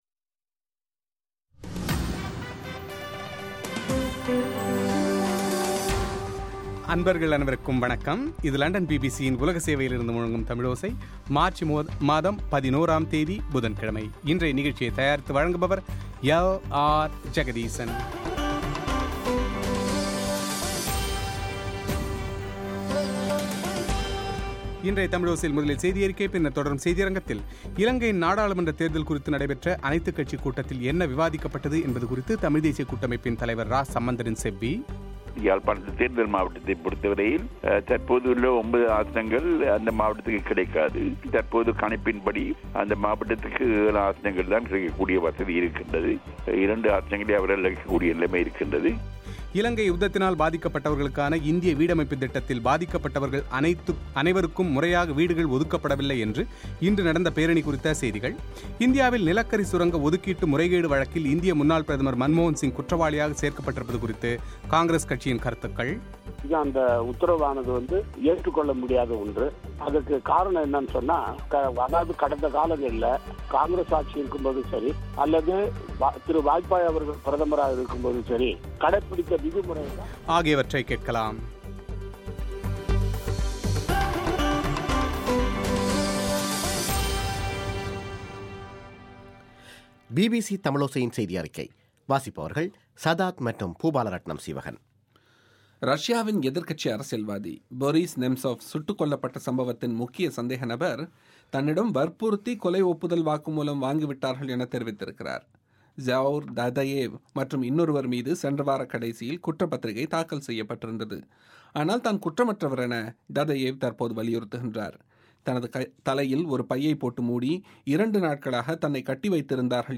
இலங்கையின் நாடாளுமன்றத் தேர்தல் குறித்து நடைபெற்ற அனைத்துக்கட்சிக் கூட்டத்தில் என்ன விவாதிக்கப்பட்டது என்பது குறித்து தமிழ்த் தேசிய கூட்டமைப்பின் தலைவர் இரா சம்பந்தரின் செவ்வி;